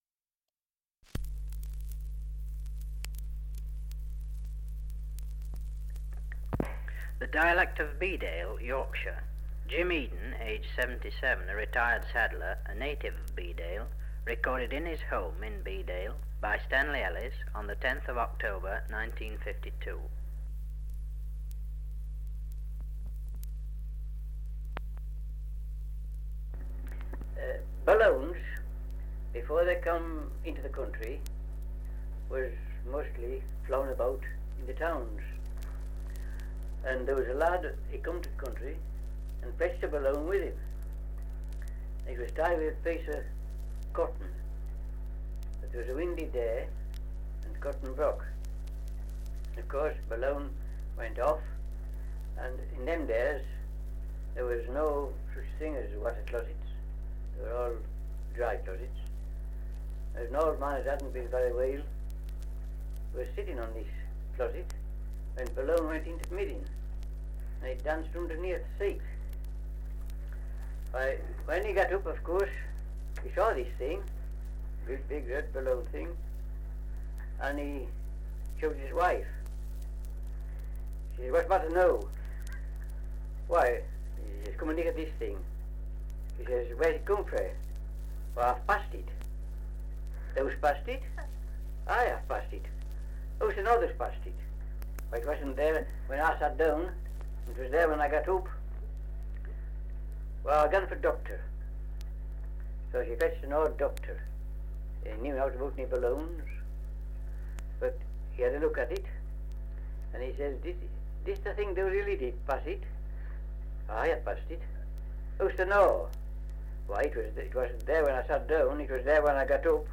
Survey of English Dialects recording in Bedale, Yorkshire
78 r.p.m., cellulose nitrate on aluminium